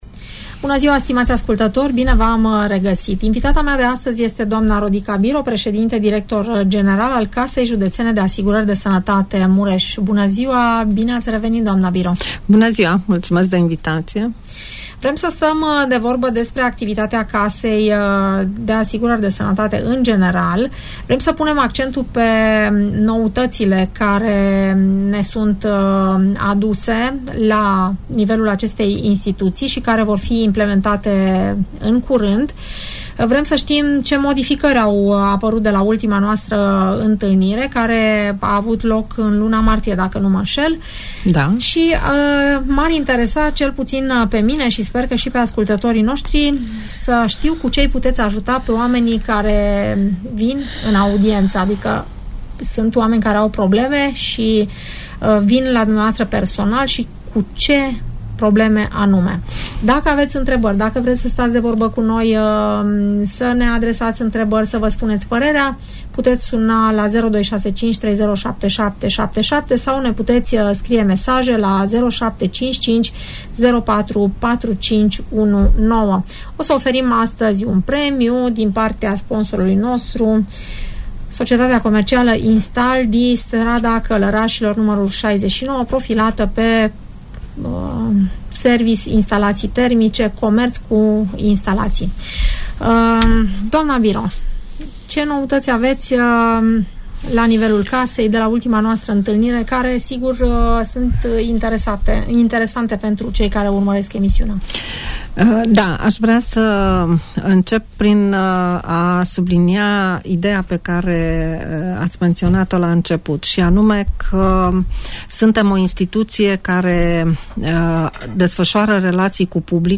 Doamna Rodica Biro, președinte director general al Casei Județene de Asigurări de Sănătate Mureș, vine la Radio Tg Mureș să ne informeze care sunt noutățile care vor intra în vigoare de la 1 iulie.